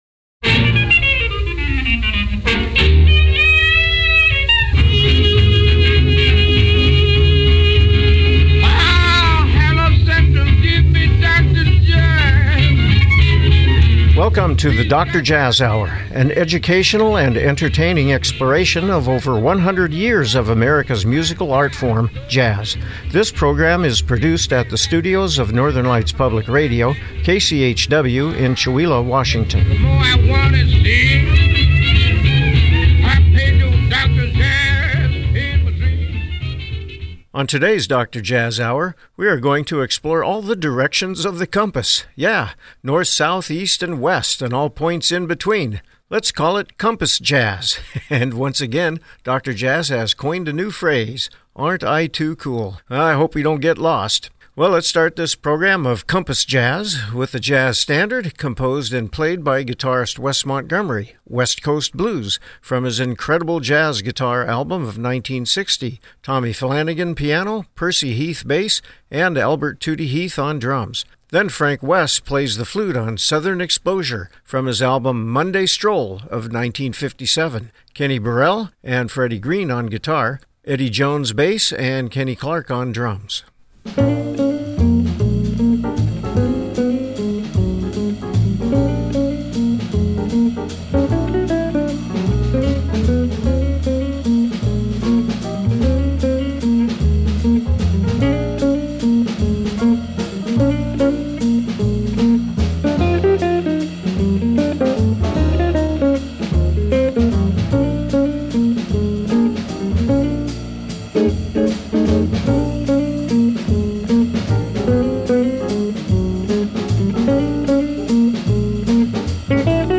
A-Infos Radio Project - DJH 037 - Compass Jazz
Program Type: Music